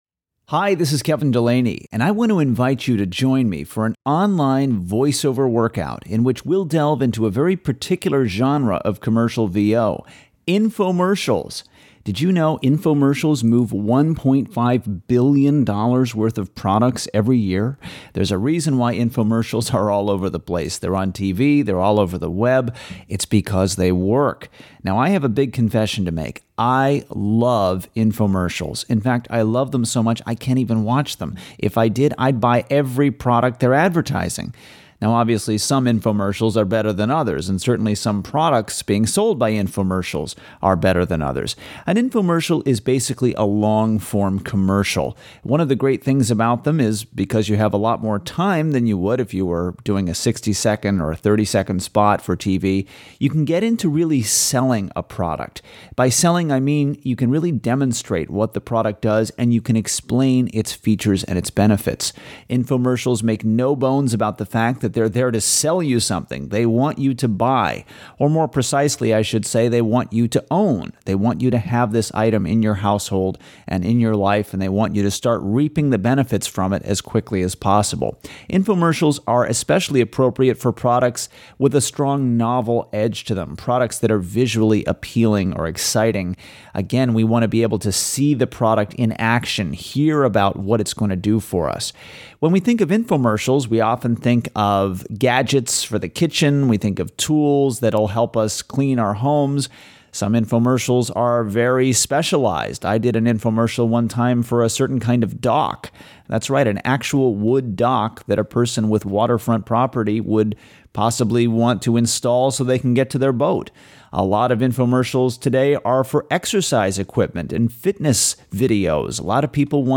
Infomercial_VO_Workout.mp3